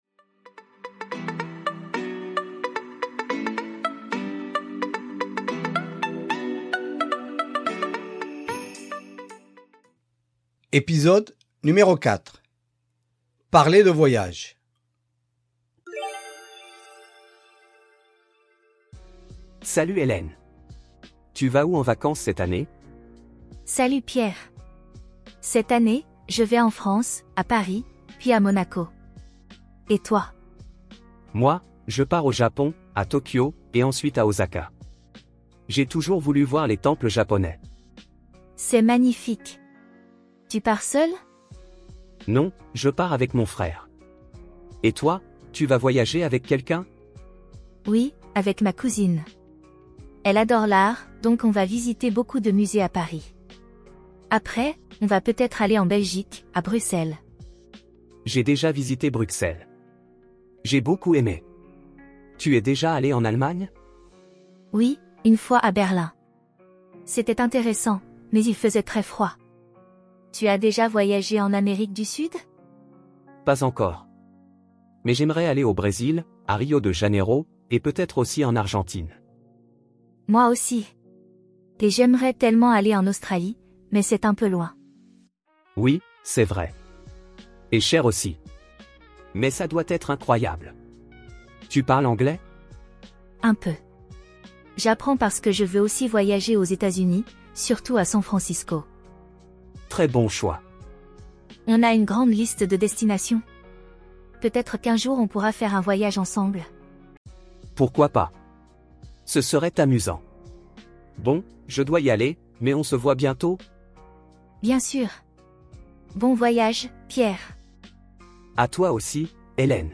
Vous aimez voyager alors voici un petit dialogue conçu essentiellement pour les débutants en langue française.